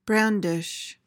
PRONUNCIATION: (BRAN-dish) MEANING: verb tr.: To hold or wave something (especially a weapon), in a threatening or triumphant manner. noun: The act of waving or displaying something in an ostentatious or boastful manner.
brandish.mp3